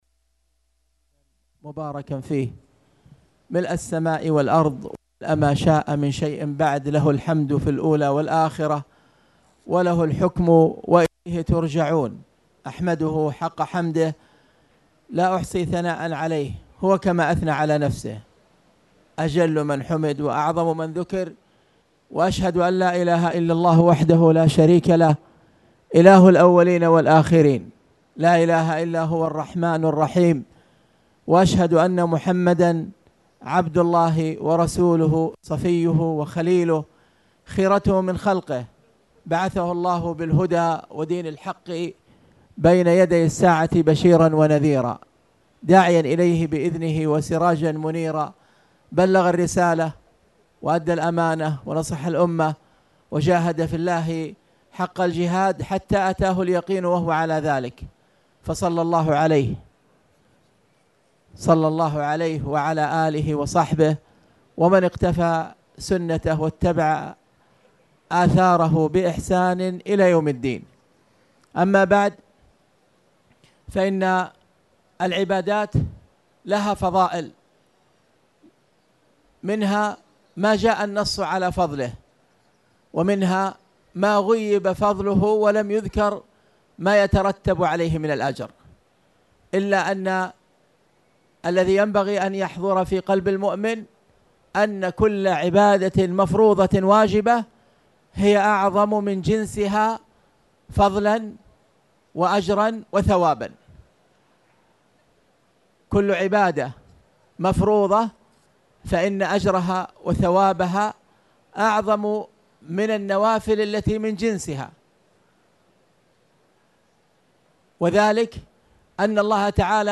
تاريخ النشر ١٦ صفر ١٤٣٨ هـ المكان: المسجد الحرام الشيخ